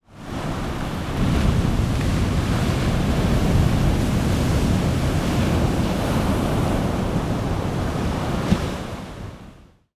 Bei Klangbeispiel 01 handelt es sich um eine unbearbeitete Tonaufnahme von Meeresrauschen. Klangbeispiel 02 sind die daraus erzeugten Herzschlag SFX.
In unserem Beispiel haben wir Meereswellen im Abstand von etwa 30m an einem einsamen Strand in Costa Rica aufgenommen.